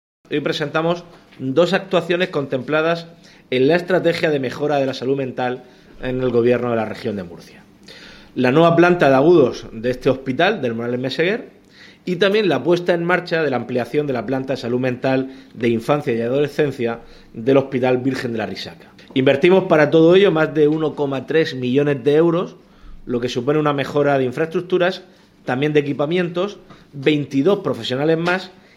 El presidente del Gobierno de la Región de Murcia, Fernando López Miras, visitó hoy la nueva planta de enfermos agudos de la Unidad de Psiquiatría y Salud Mental del Hospital Morales Meseguer y anunció la apertura de la planta de Salud Mental de Infancia y Adolescencia del Hospital Virgen de la Arrixaca.
Sonido/ Declaración 1 del presidente de la Comunidad, Fernando López Miras, sobre los nuevos recursos de Salud Mental puestos en marcha por la Comunidad.